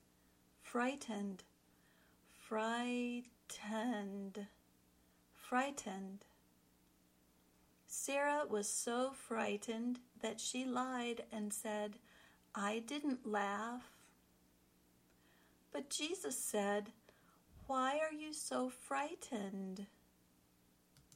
Then, listen to how it is used in the sample sentences.
ˈfraɪ tənd  (adjective)